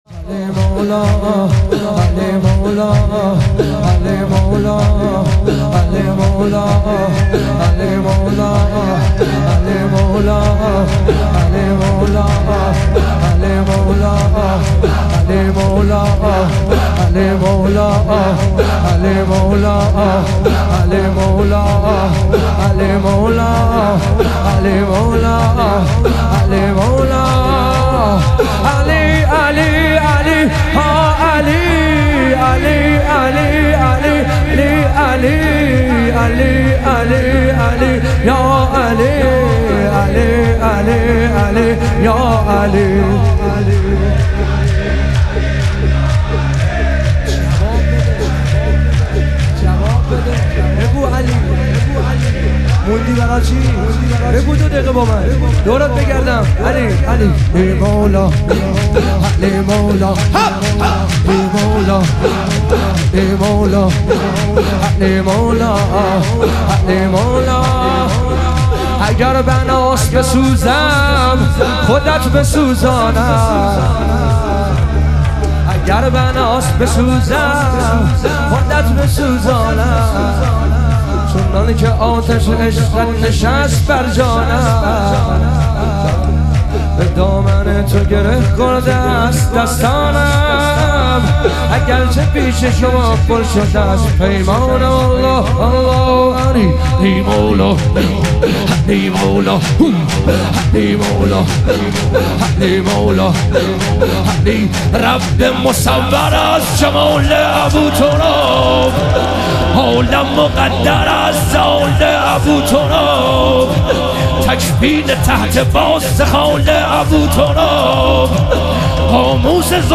لیالی قدر و شهادت امیرالمومنین علیه السلام - شور